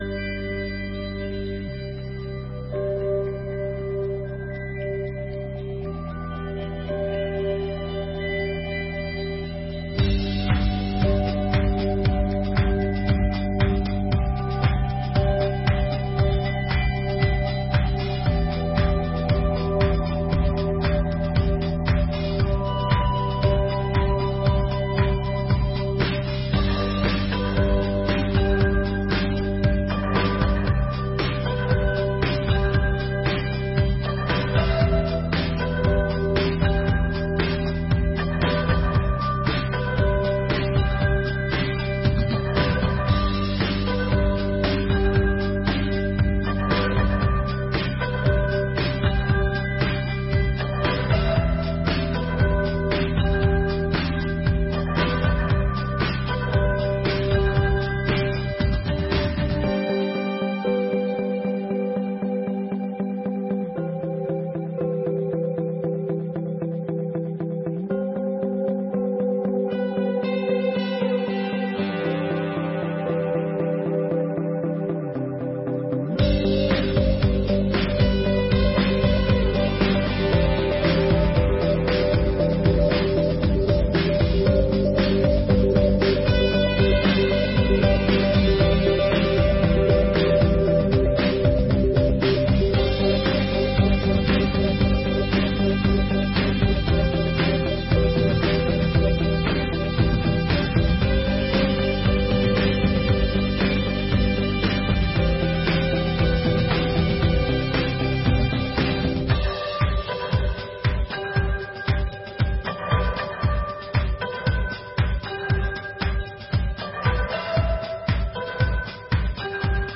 34ª Sessão Ordinária de 2023